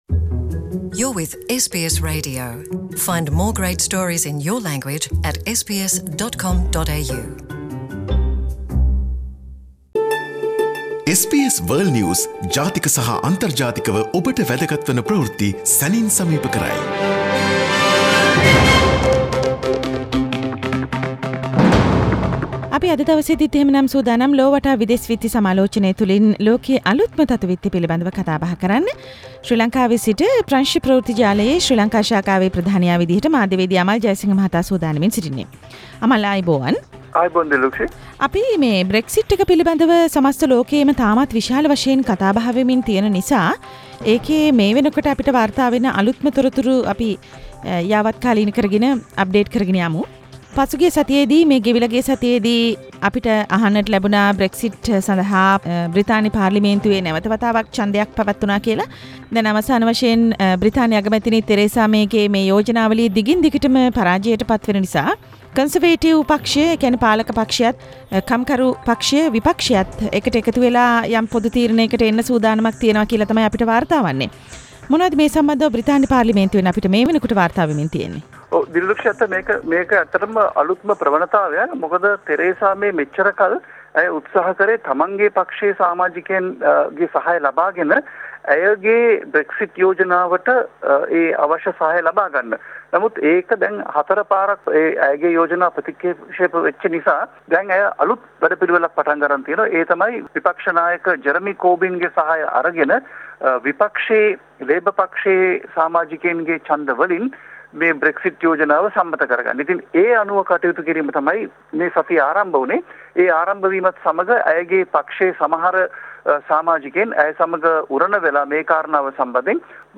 World news wrap Source: SBS Sinhala